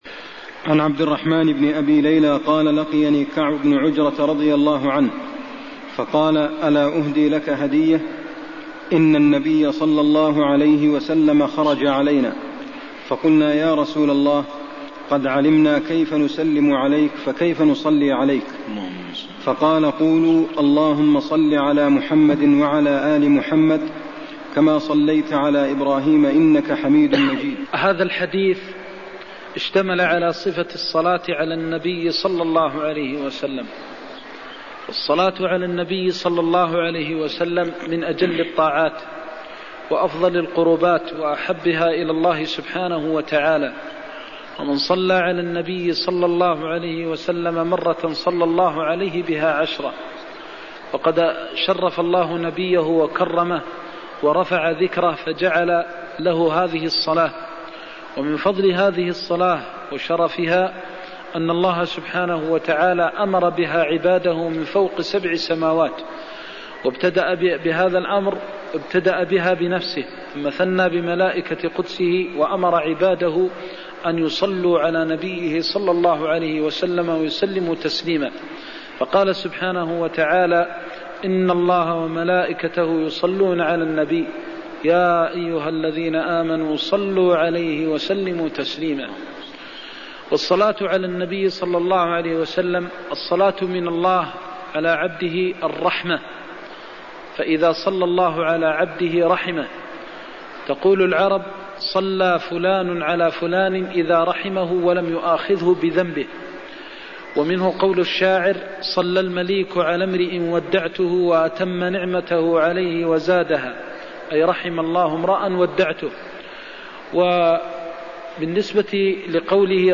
المكان: المسجد النبوي الشيخ: فضيلة الشيخ د. محمد بن محمد المختار فضيلة الشيخ د. محمد بن محمد المختار صفة الصلاة على النبي (115)